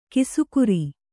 ♪ kisukuri